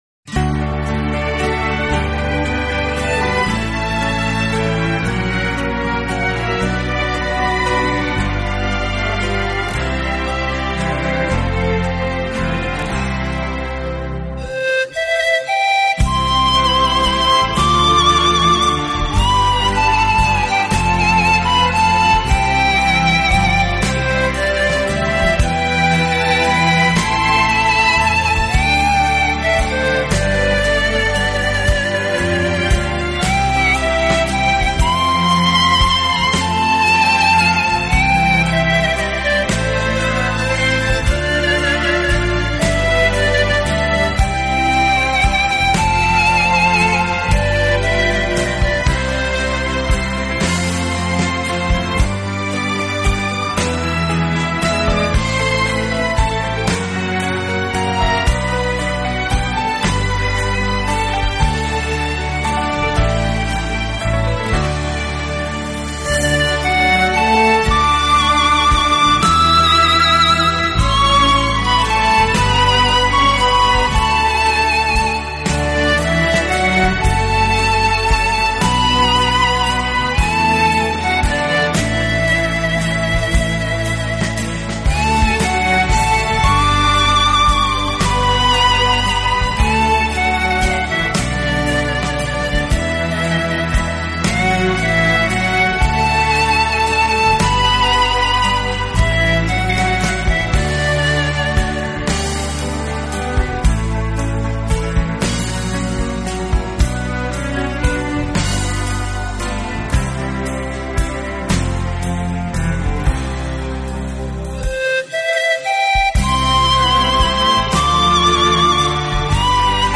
轻音乐作品的旋律优美动听、清晰流畅，节奏鲜明轻快，音色丰富多彩，深受